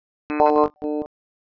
Ниже приведены временные реализации слова при разных условиях
Воспроизводится набор кадров после корреляционной обработки.
Соседние кадры отличаются друг от друга больше, чем на 1200 условных единиц в метрике огибающей.